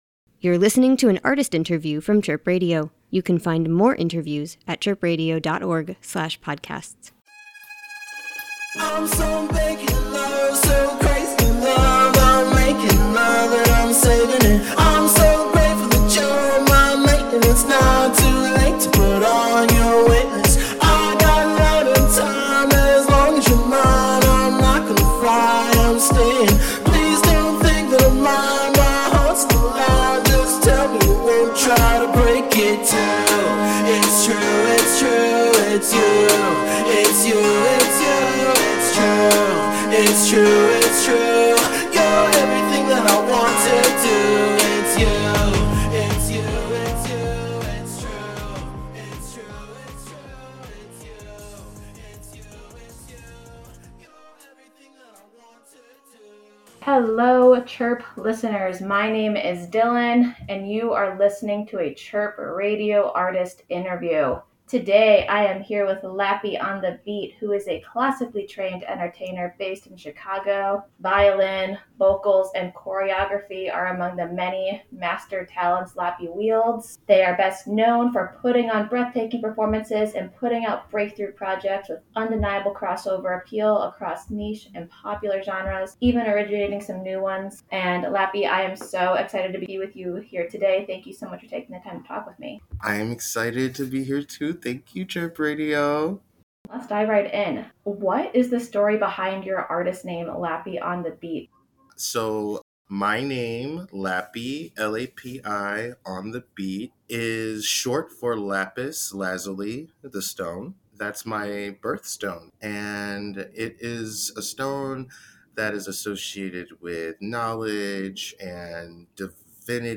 Everyday Specials Interview.